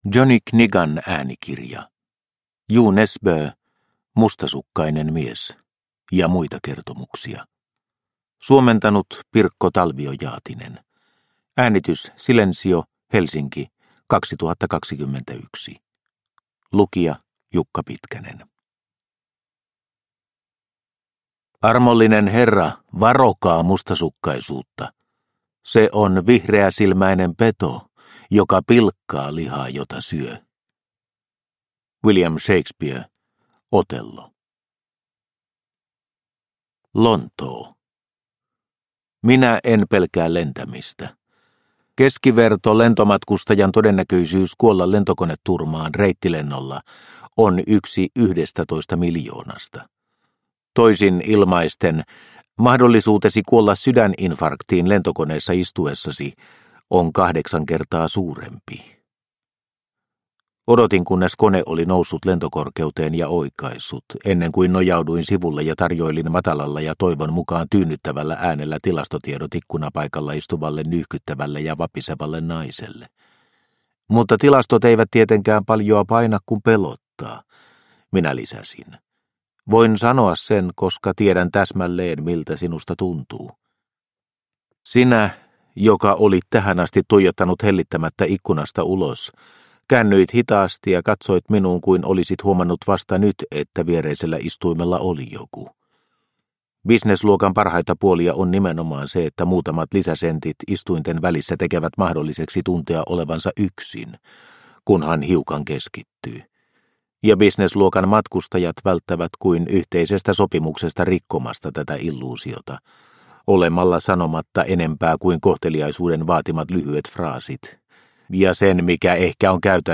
Mustasukkainen mies – Ljudbok – Laddas ner